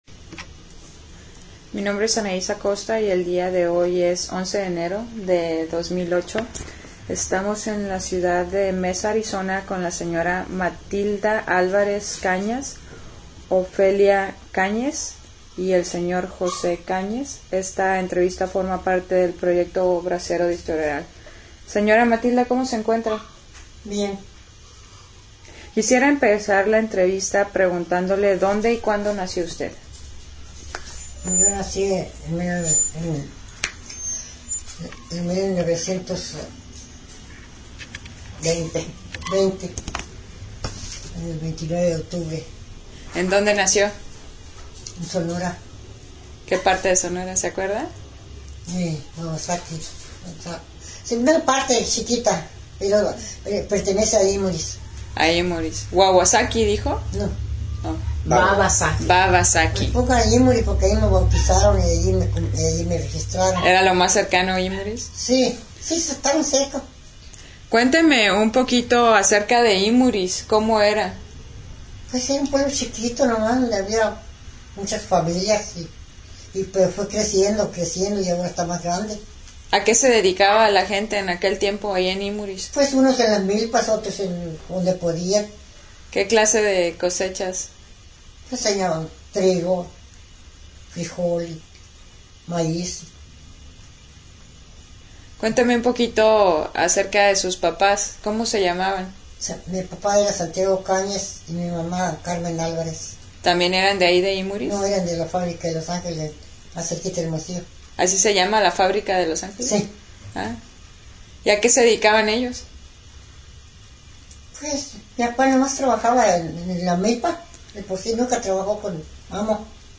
Location Mesa, Arizona